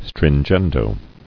[strin·gen·do]